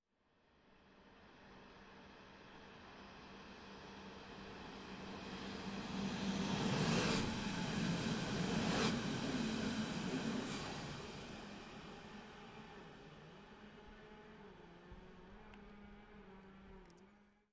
在德国柏林Priesterweg经过的列车 " S Bahn城市列车经过 4
描述：SBahn城市列车通过。在铁路轨道上方的桥上听到了。 2016年9月在柏林Priesterweg以Zoom HD2录制成90°XY
Tag: 铁路 城市列车 铁路 铁路 铁路 火车 电动火车 铁路 现场记录 轻轨 乘客列车